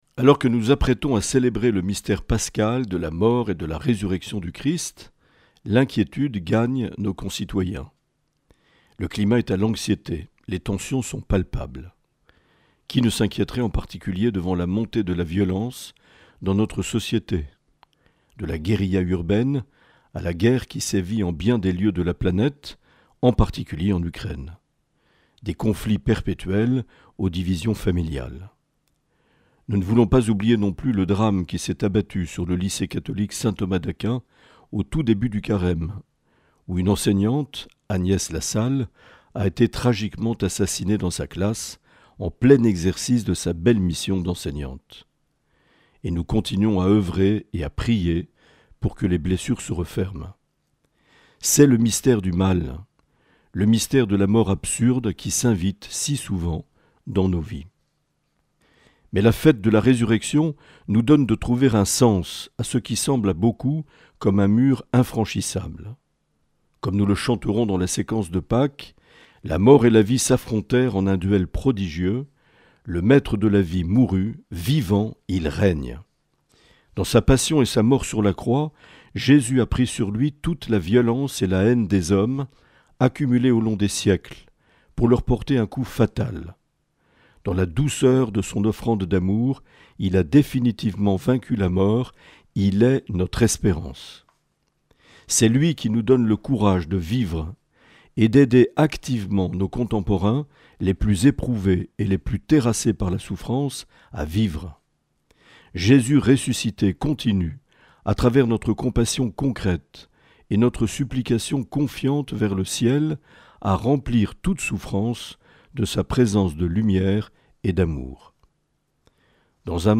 évêque de Bayonne, Lescar et Oloron.